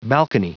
Prononciation du mot balcony en anglais (fichier audio)
Prononciation du mot : balcony